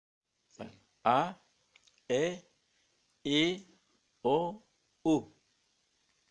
描述：Voz masculina79años
Tag: 声乐 雄性 语音